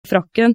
frakken uten preaspirasjon. (ee)